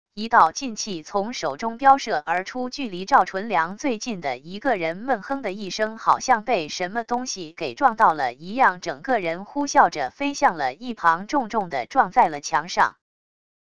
一道劲气从手中飙射而出 距离赵纯良最近的一个人闷哼的一声 好像被什么东西给撞到了一样 整个人呼啸着飞向了一旁 重重的撞在了墙上wav音频生成系统WAV Audio Player